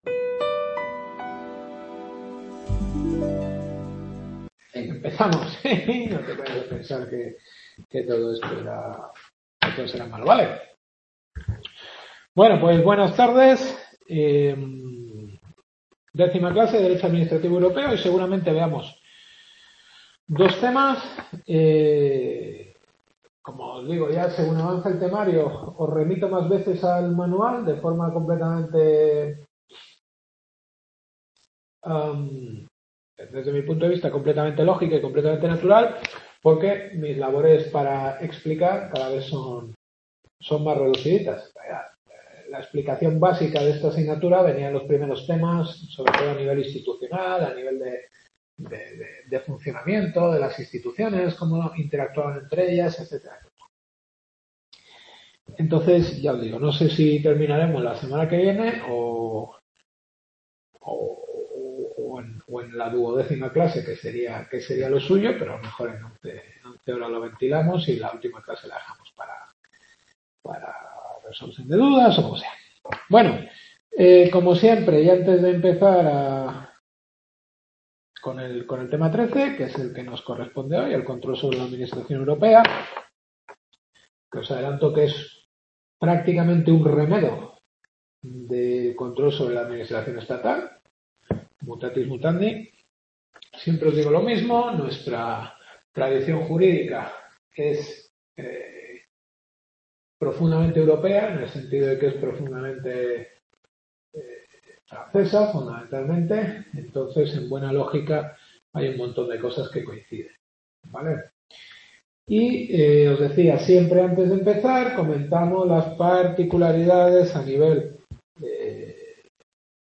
Derecho administrativo europeo. Décima clase.